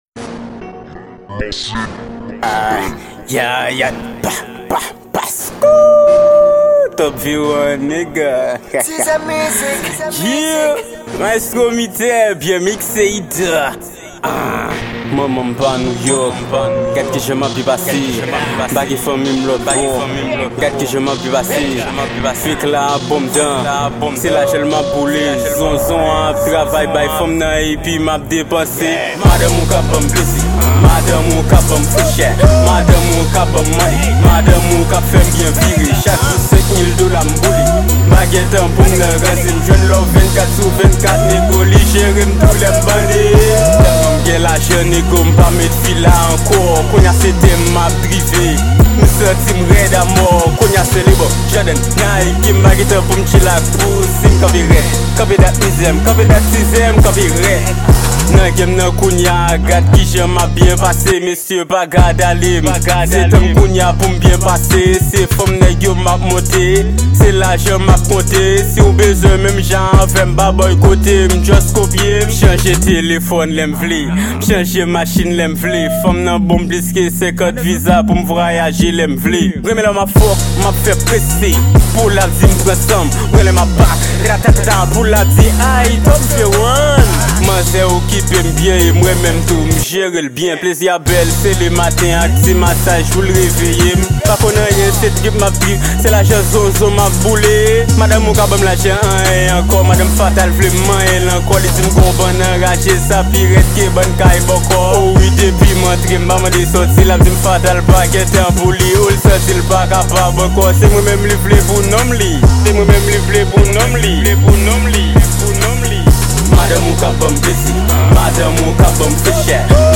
New trap
Genre : RAP